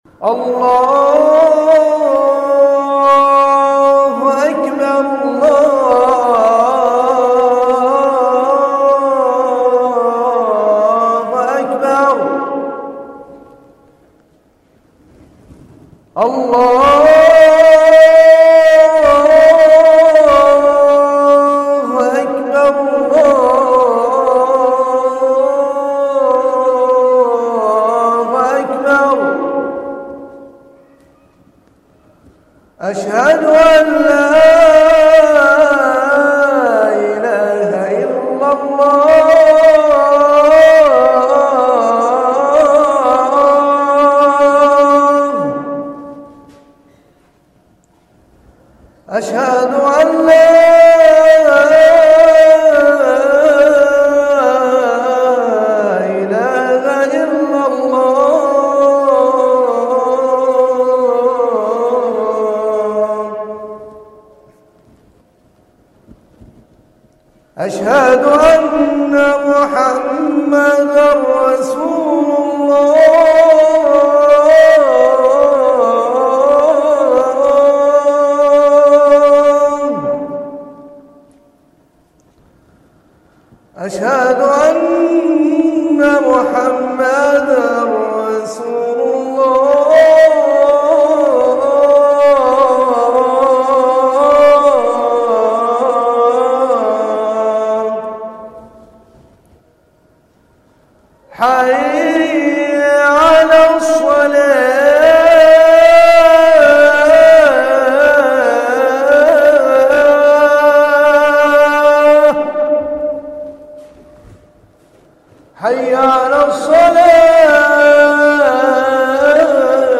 أذان